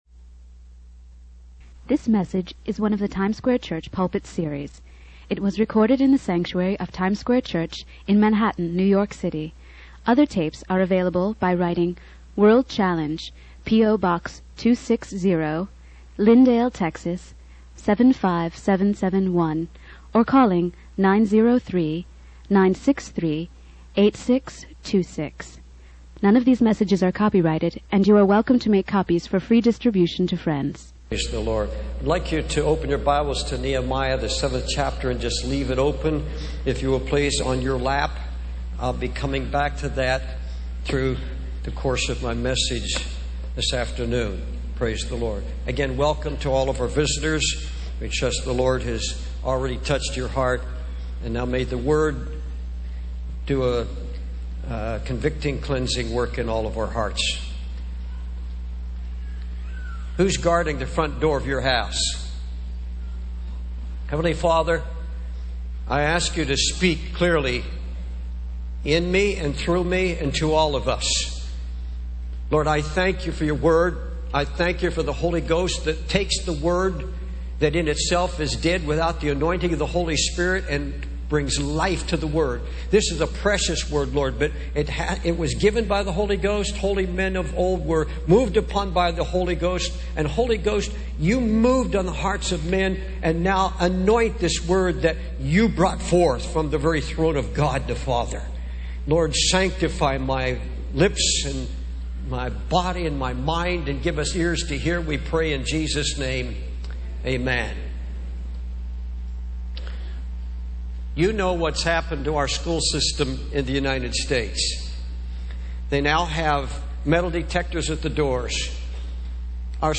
This sermon emphasizes the importance of guarding our homes and families against the influences of the world, addressing issues like the spiritual battle for our children, the need for parents to be vigilant gatekeepers, and the power of prayer and faith in raising godly children. It also touches on the dangers of bitterness, the impact of media and music on our youth, and the call for parents to establish family altars and seek God's wisdom in guiding their children.